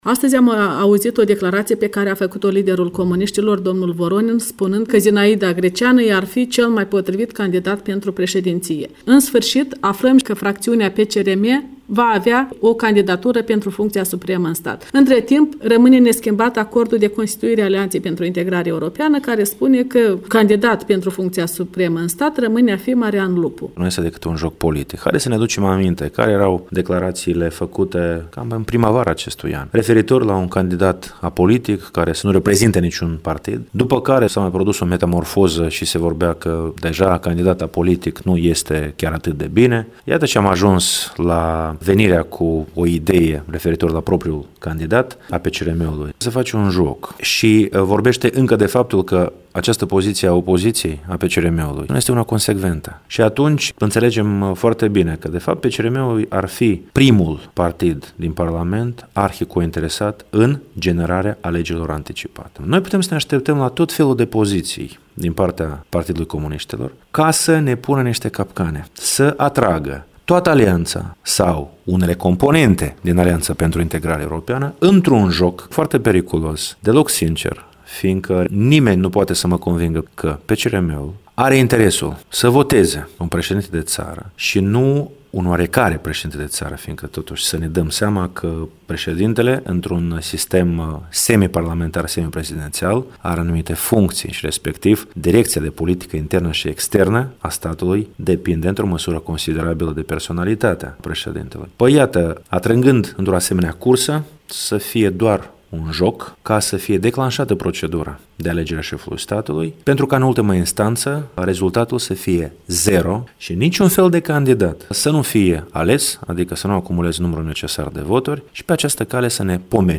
Interviu cu Marian Lupu